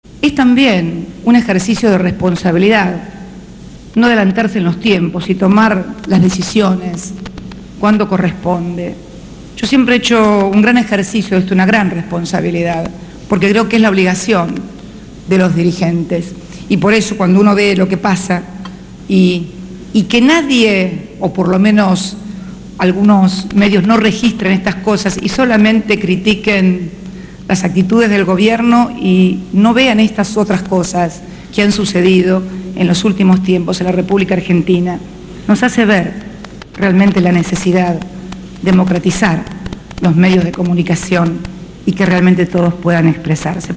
En la Casa de Gobierno, en el marco de la inauguración de la TV Digital para Jujuy y Entre Ríos, la Presidenta anunció su candidatura presidencial.